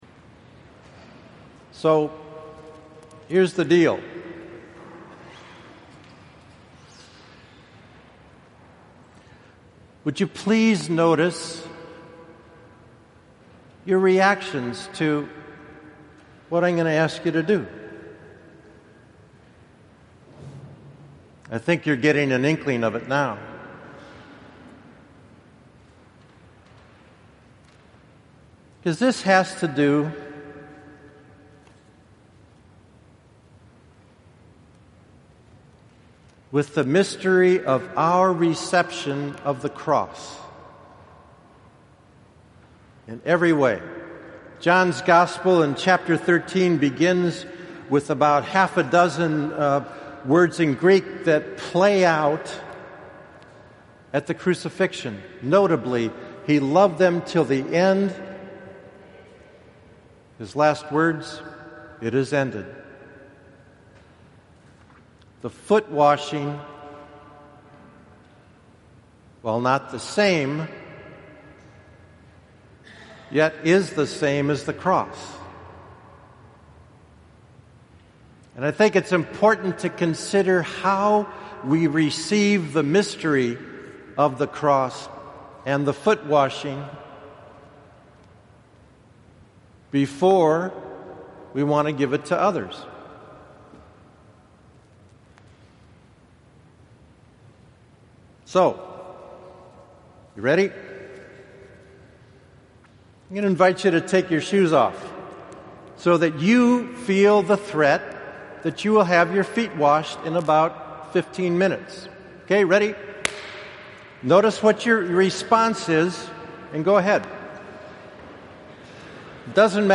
Holy Thursday Homily – Mass of the Lord’s Supper